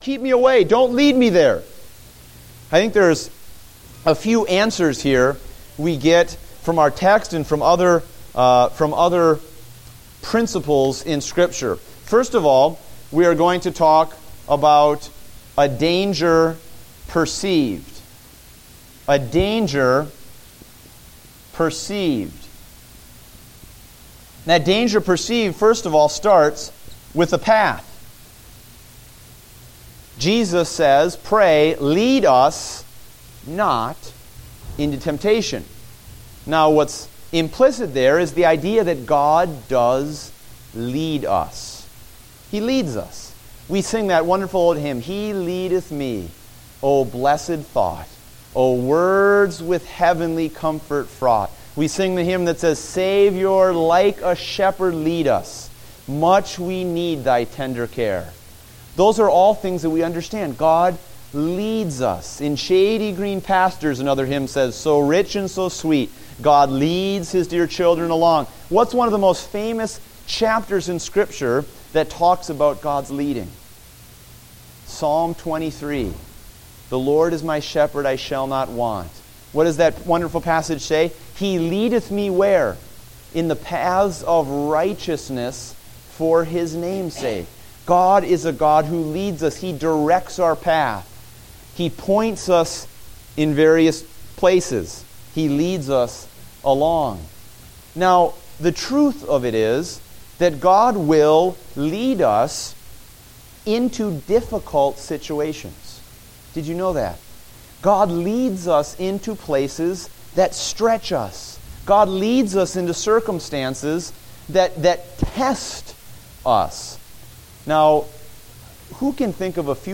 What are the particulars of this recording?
Date: August 2, 2015 (Adult Sunday School)